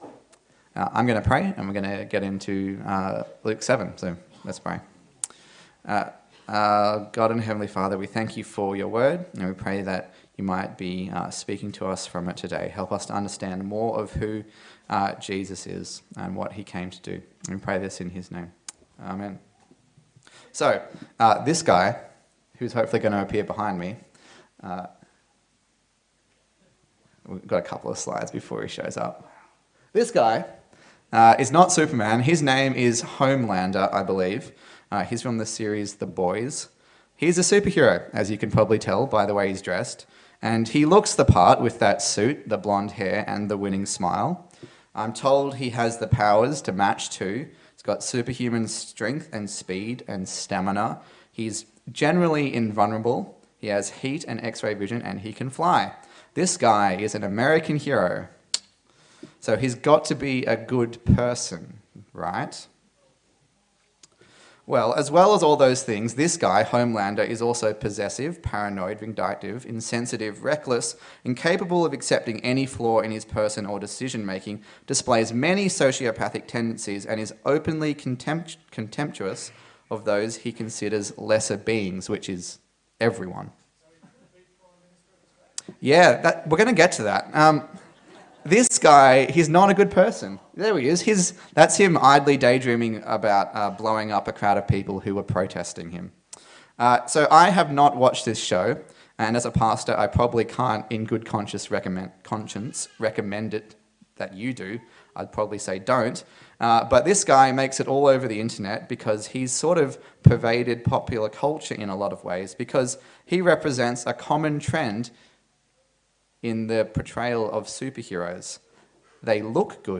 Luke Passage: Luke 7:1-17 Service Type: Sunday Service